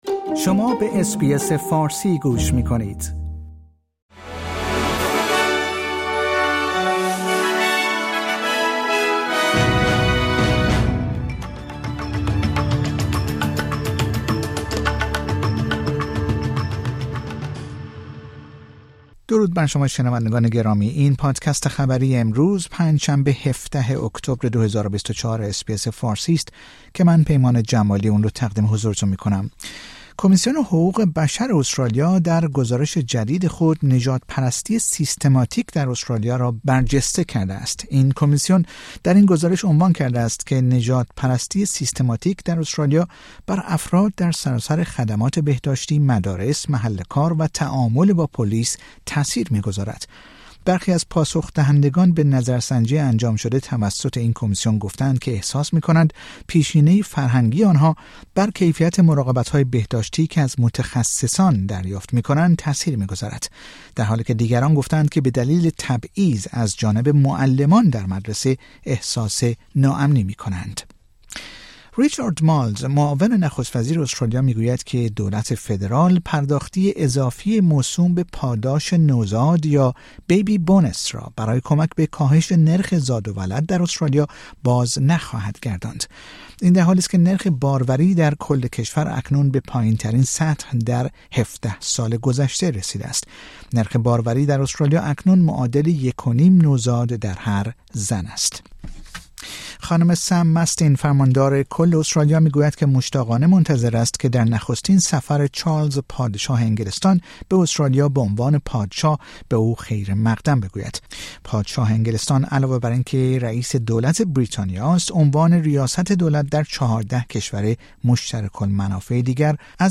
در این پادکست خبری مهمترین اخبار استرالیا در روز پنج شنبه ۱۷ اکتبر ۲۰۲۴ ارائه شده است.